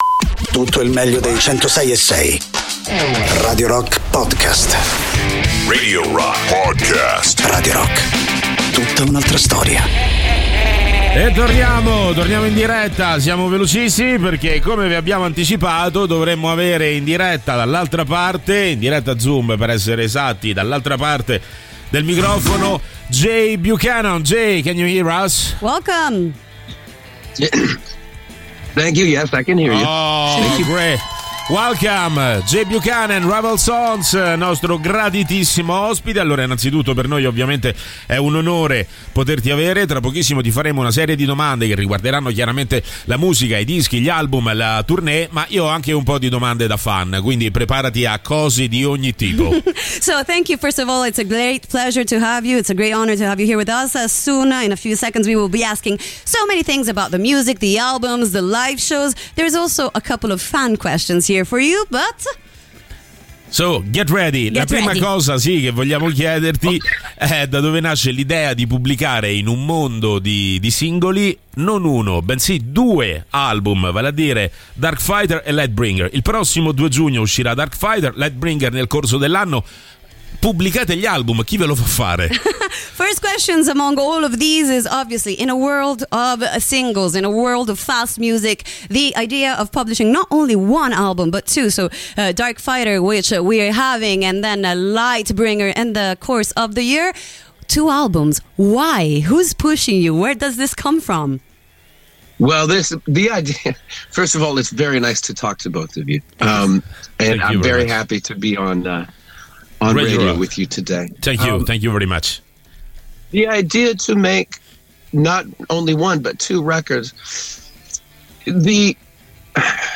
Interviste: Jay Buchanan (Rival Sons) (04-05-23)
Jay Buchanan dei Rival Sons, ospite telefonico